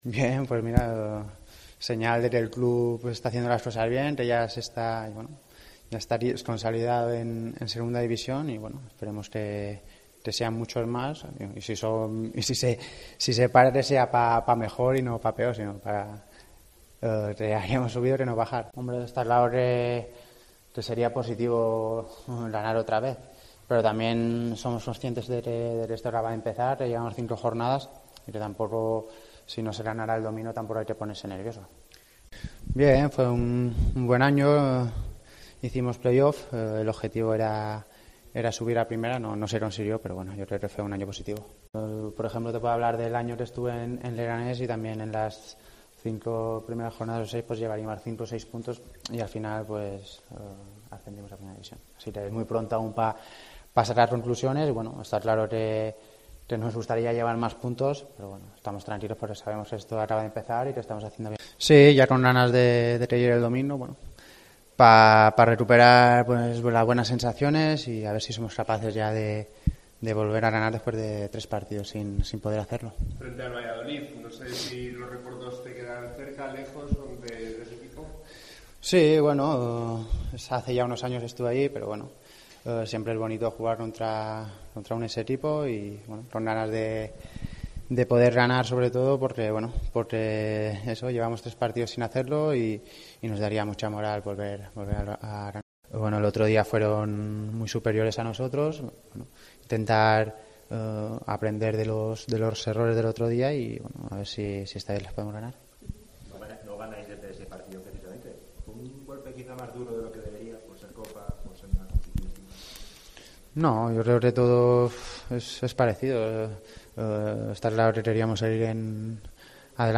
Escuchamos a Lluís Sastre en zona mixta